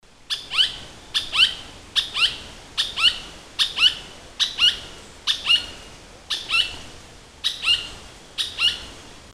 Barranqueiro-de-olho-branco (Automolus leucophthalmus)
Nome em Inglês: White-eyed Foliage-gleaner
Fase da vida: Adulto
Localidade ou área protegida: Refugio Privado de Vida Silvestre Yacutinga
Condição: Selvagem
Certeza: Gravado Vocal